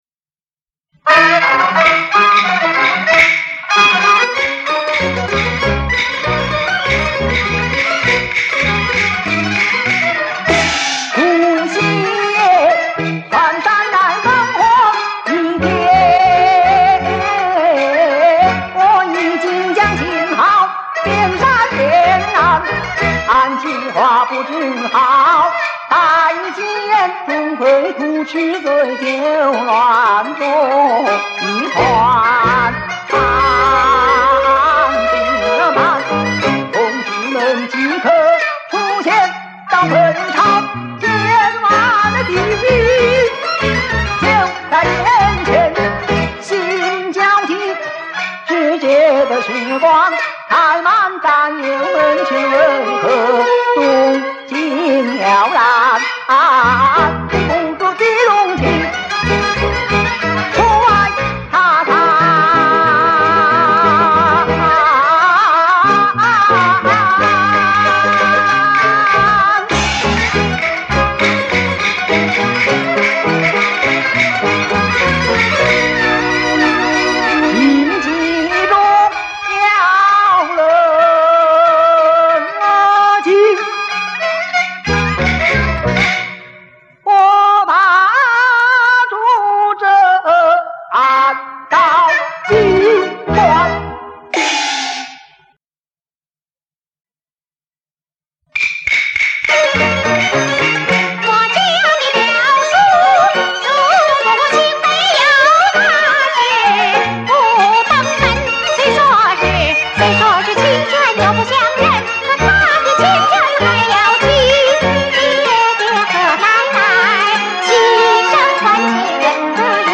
试听曲 01+02+03+07+08